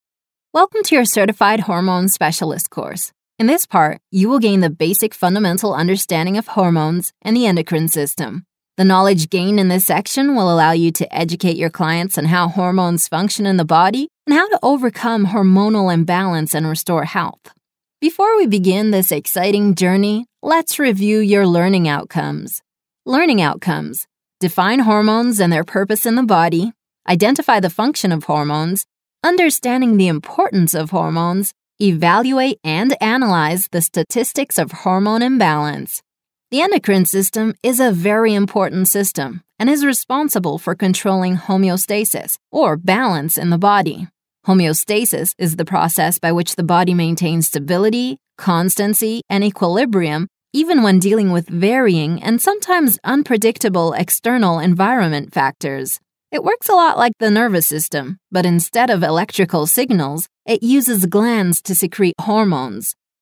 Female
Medical Narrations
warm, conversational, professional, articulate, educational, enthusiastic, knowledgeable, intellectual, intelligent, Narrator, Business, e-learning, educational, training, narration, informative, engaging, technical, industrial, scientific